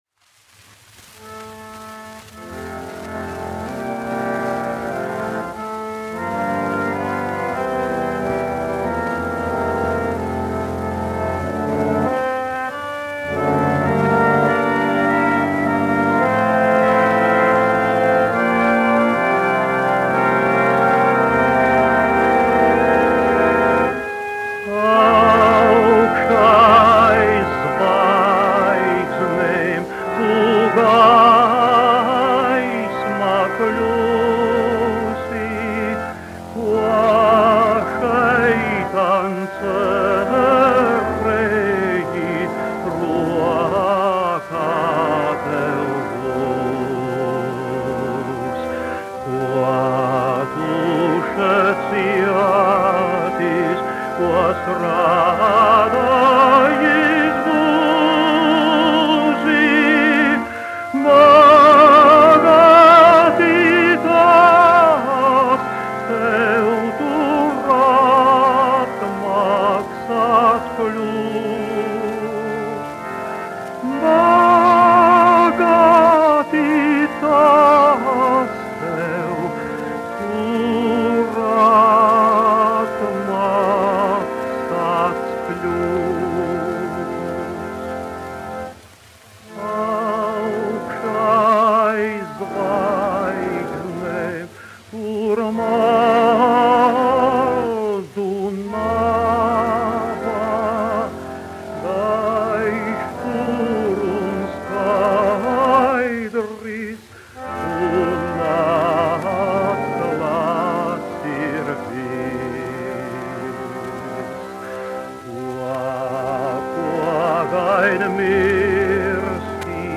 Augšā aiz zvaigznēm : korālis
Mariss Vētra, 1901-1965, dziedātājs
Alfrēds Kalniņš, 1879-1951, instrumentālists
1 skpl. : analogs, 78 apgr/min, mono ; 25 cm
Garīgās dziesmas